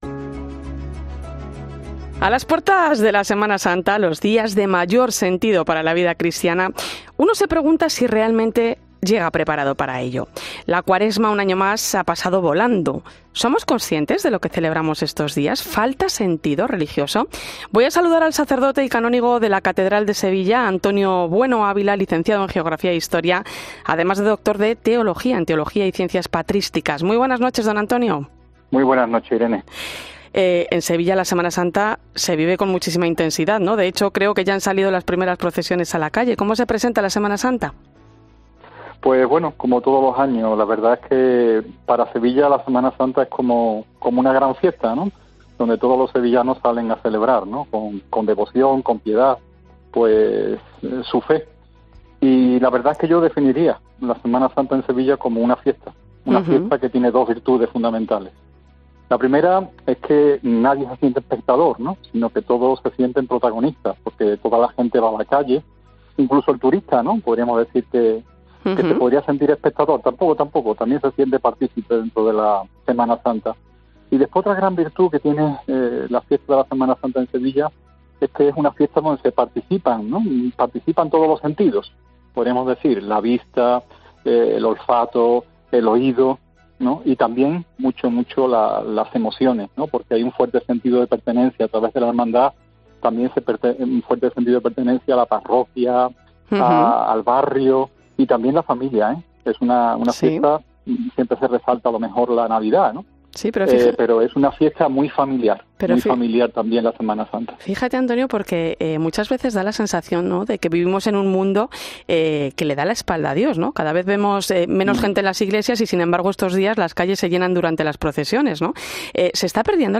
En 'La Linterna de la Iglesia' hablamos con el sacerdote y canónigo de la Catedral de Sevilla en este viernes de Pasión